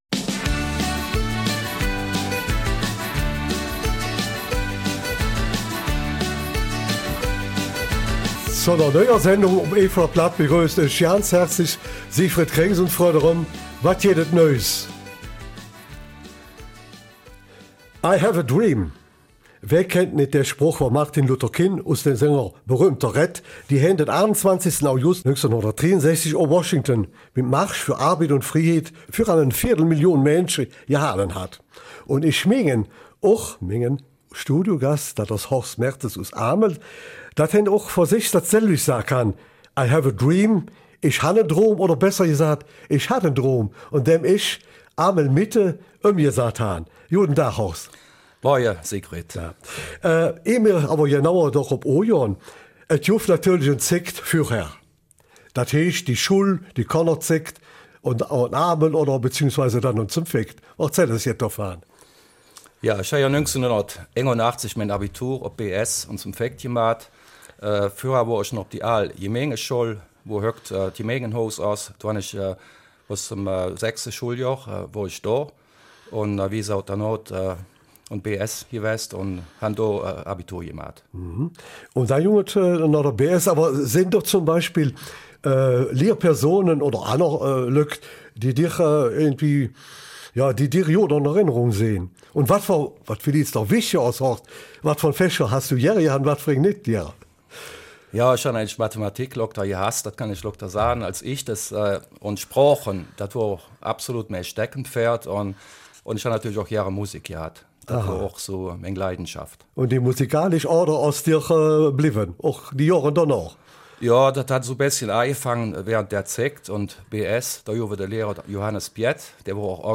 Eifeler Mundart